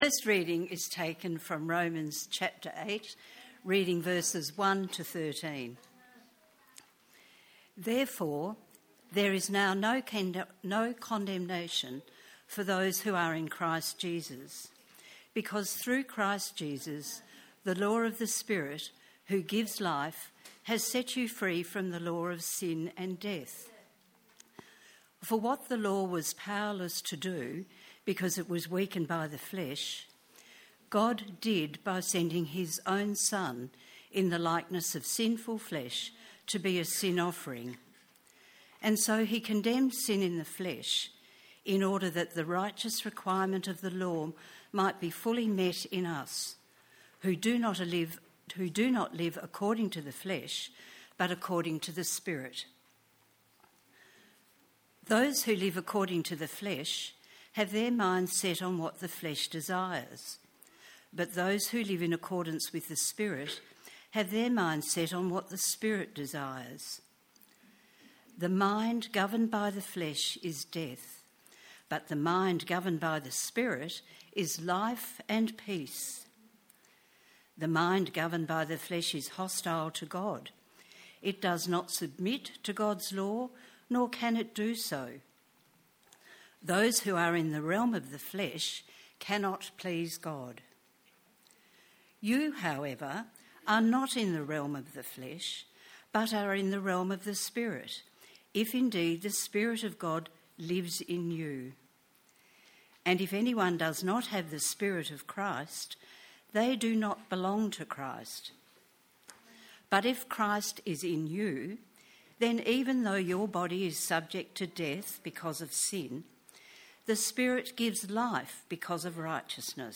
Sermons | Living Water Anglican Church